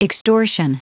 Prononciation du mot extortion en anglais (fichier audio)
Prononciation du mot : extortion